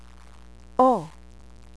cholam chaser "o" as in alone